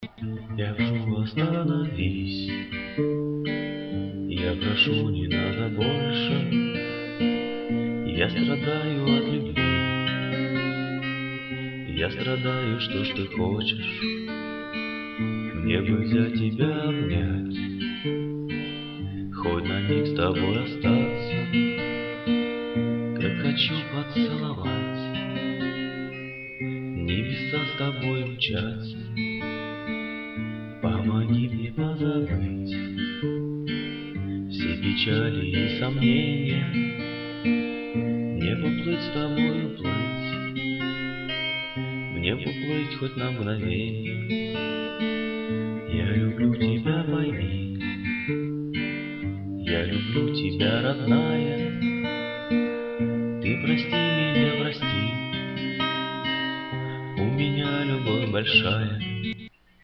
Песня
Фоном играет она записанная под гитару